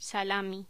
Locución: Salami
voz
Sonidos: Voz humana